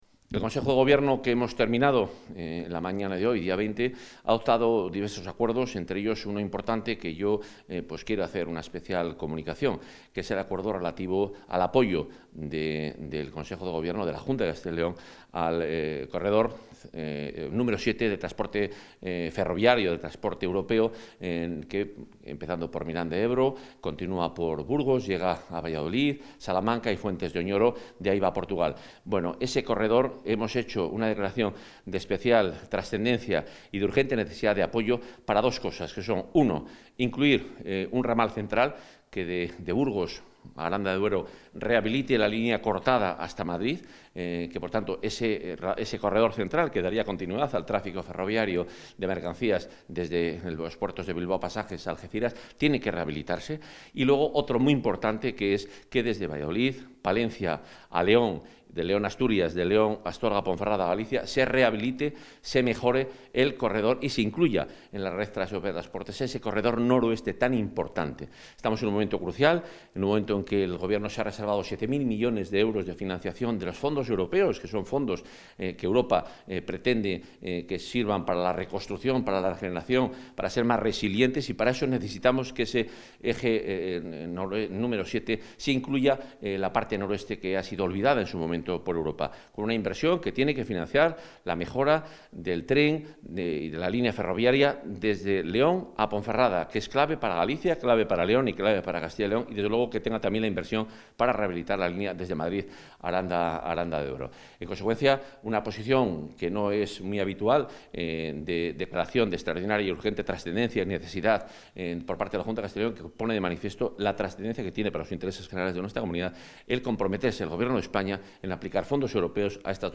Valoraciones consejero.
Consejo de Gobierno del 20 de mayo de 2021